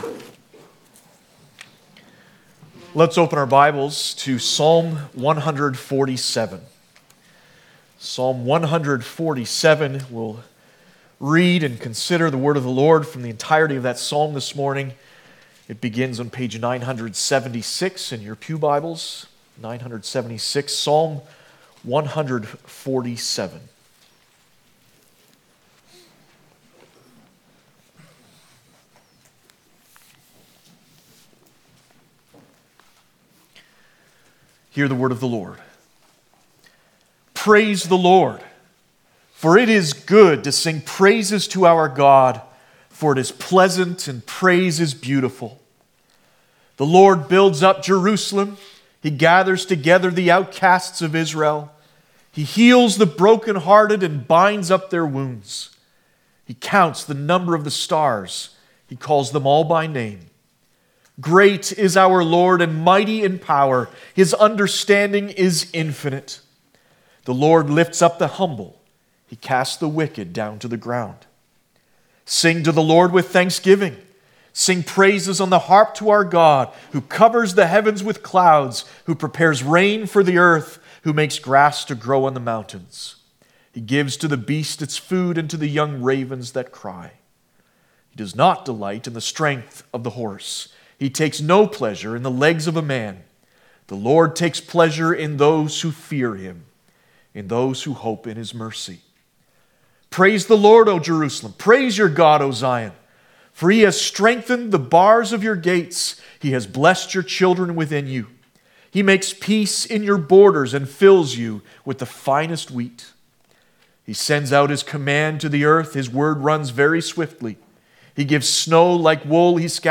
5.-Service-of-Gods-word-Oct-14-Thanksgiving.mp3